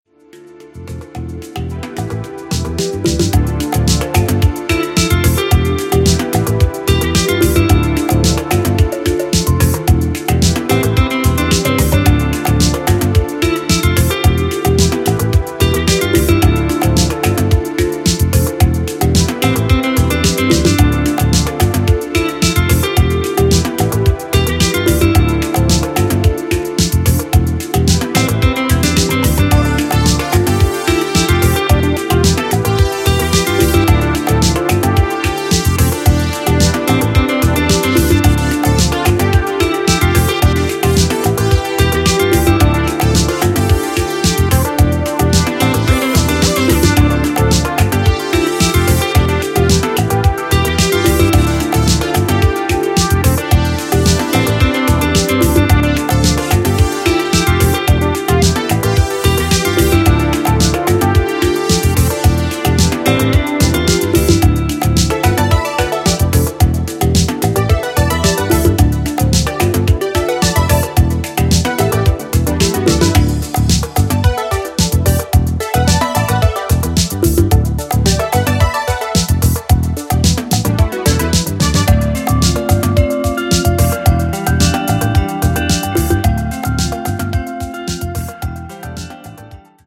ドリーム・ハウスを彷彿とさせるキラー・バレアリック・ニューディスコ/ハウス！
ジャンル(スタイル) NU DISCO / BALEARIC HOUSE